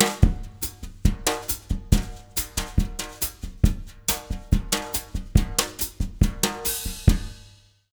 140BOSSA02-L.wav